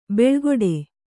♪ beḷgoḍe